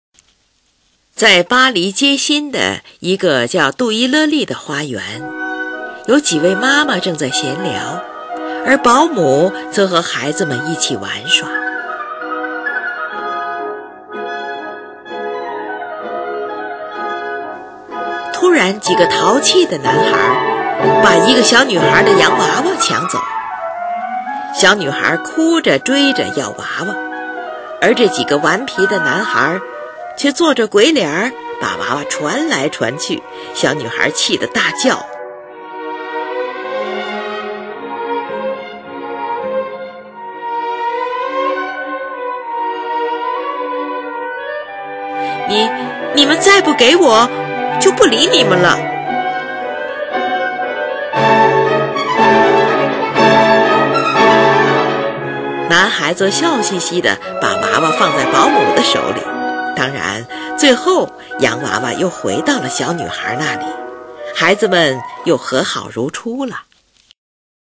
后来有几位作曲家将这首钢琴组曲改编成管弦乐曲
乐曲开始时立即传出的便是孩子的调皮、任性、固执的喊叫，这是一幅生活风俗型画面，典雅、轻巧而逼真，心理的描写也很细致。